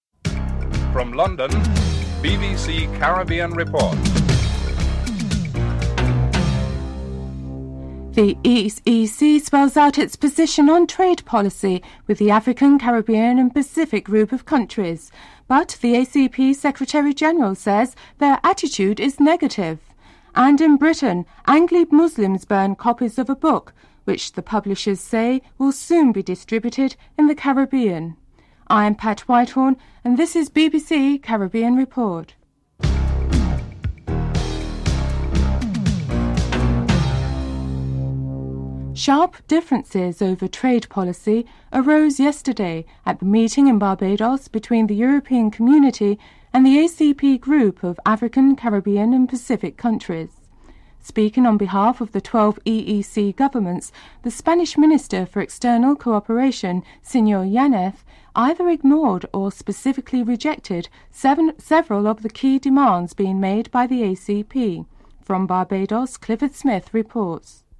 Interviews were conducted with prominent Muslims in Britain (08:03-12:53)